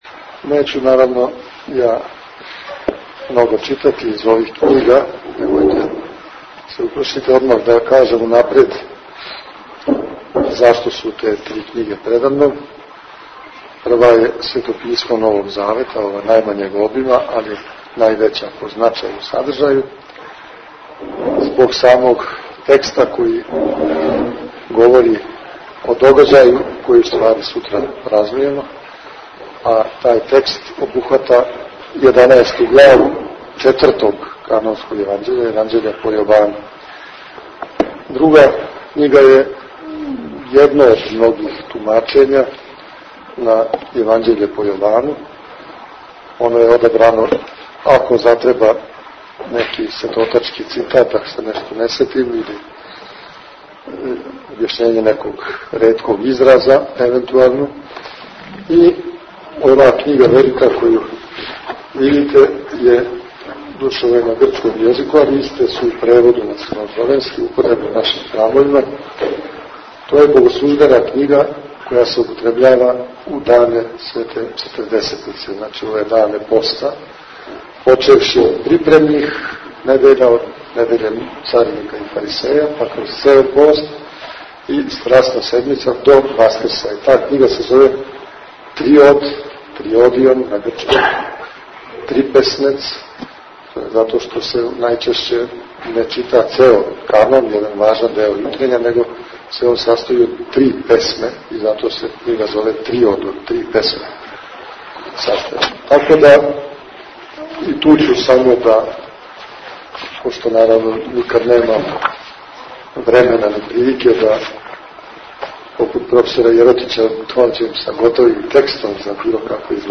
У петак, 26. марта текуће године, у свечаној сали СКЦ „Свети Сава“ у Суботици, Његово Преосвештенство Епископ бачки Господин др Иринеј беседио је на тему „Лазарева субота - библијски и литургијски смисао празника“.
Звучни запис предавања